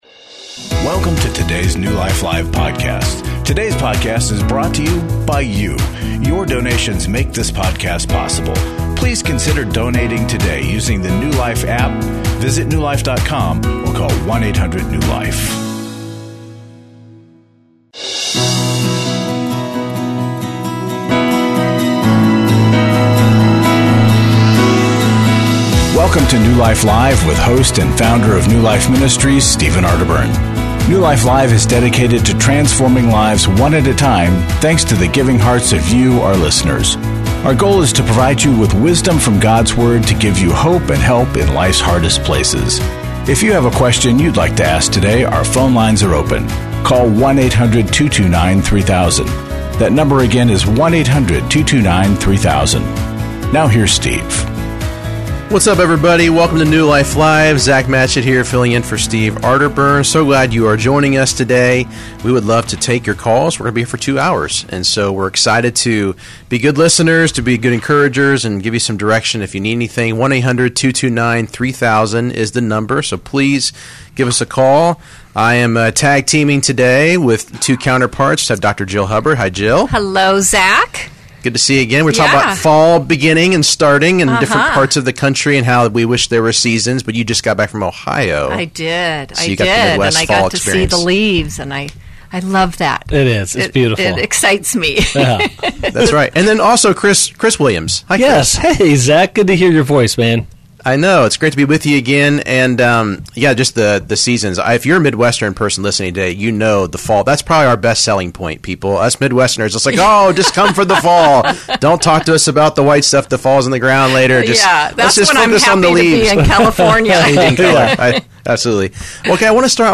Caller Questions: I need spiritual warfare help for my 9yo disabled daughter who says she sees children who aren’t there and they tell her to do bad things. Is there any difference between listening to an audio Bible versus reading the Bible?